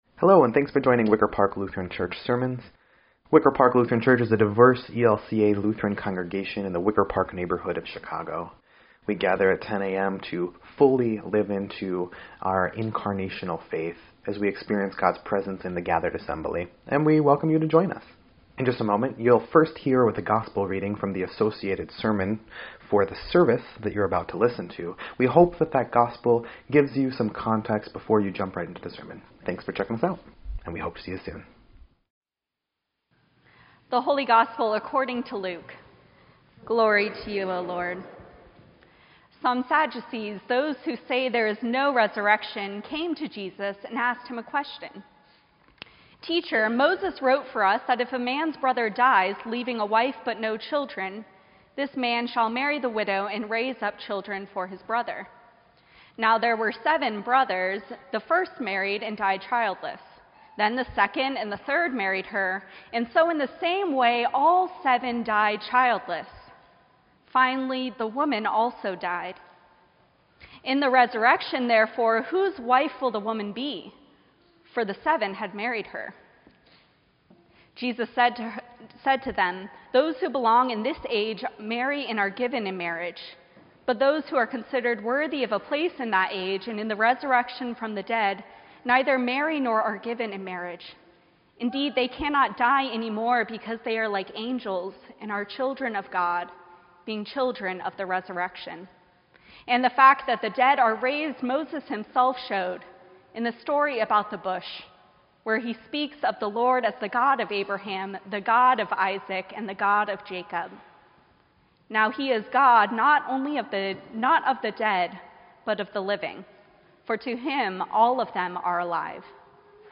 First Sunday of Advent